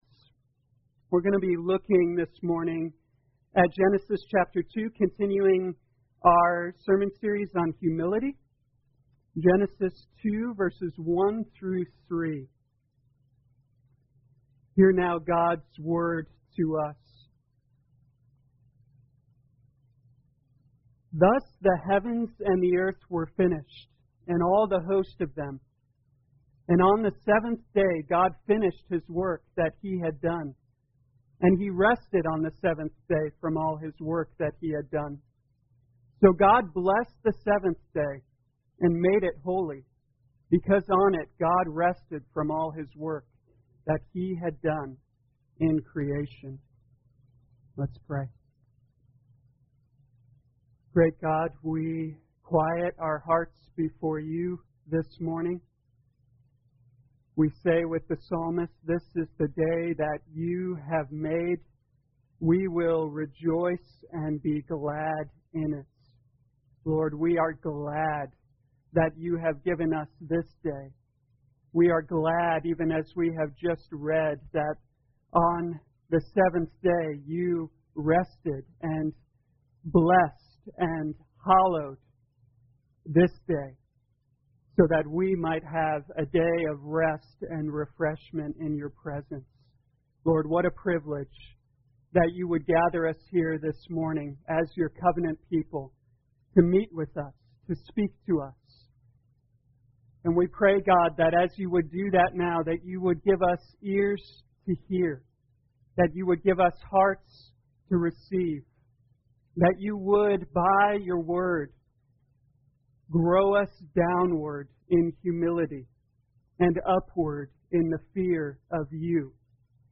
2020 Genesis Humility Morning Service Download